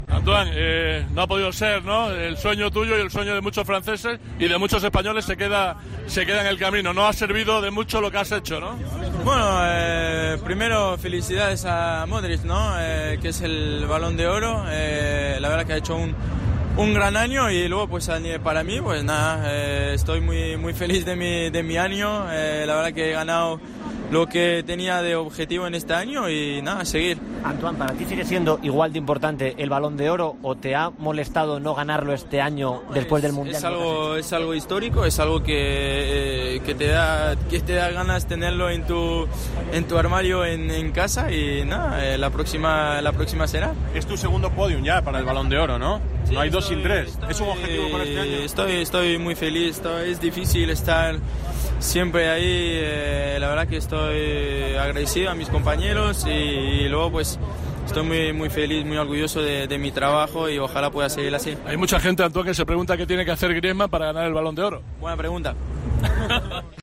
El delantero atendió a los medios españoles desplazados a París, minutos después de la gala, donde felicitó al futbolista croata: "Felicidades a Modric, ha hecho un gran año".